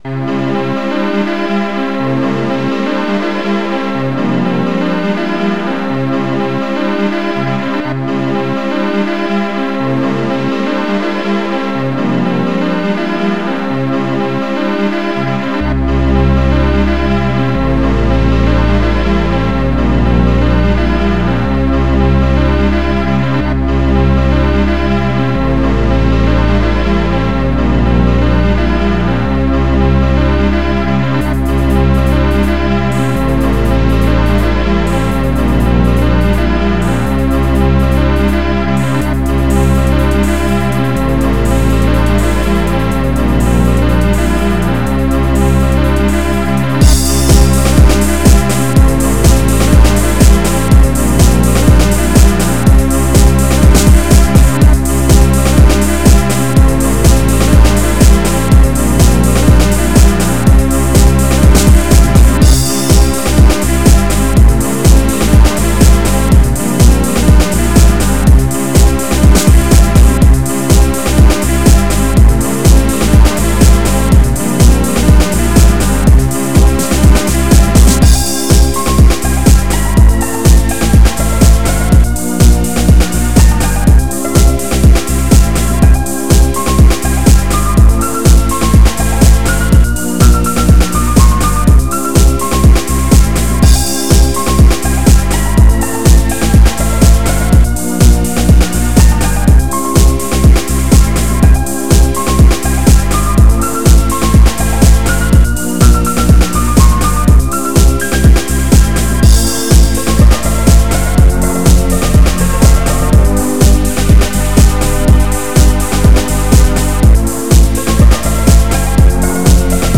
s3m (Scream Tracker 3)
this house music!